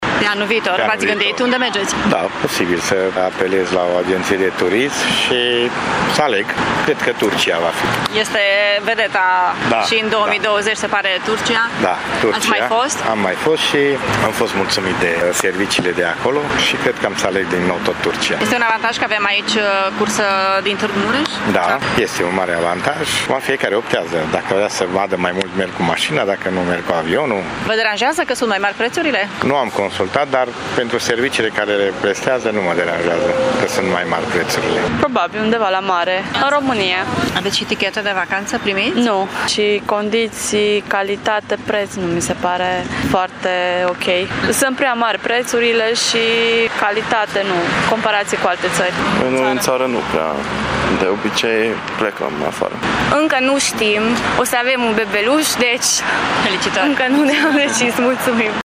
Unii târgumureșeni nu și-au făcut încă planuri de vacanță, dar sunt pregătiți să plătească mai mult pentru a merge pe litoral: